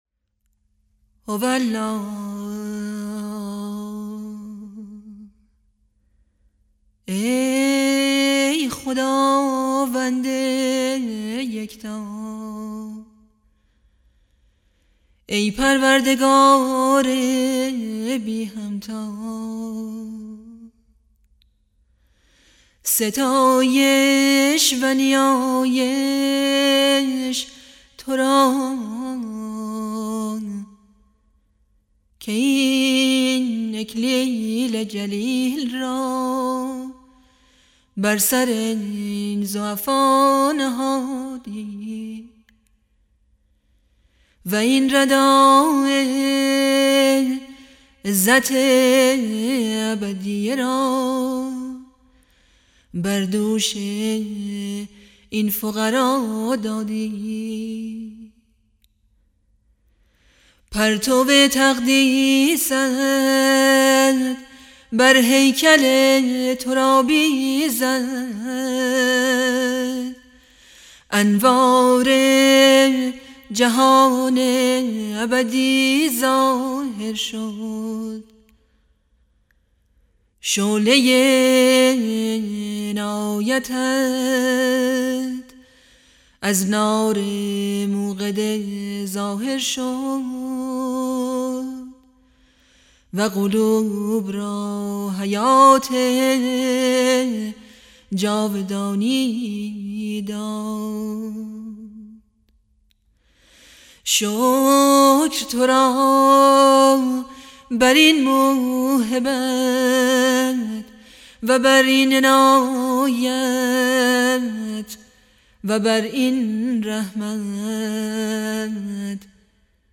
مناجات های صوتی فارسی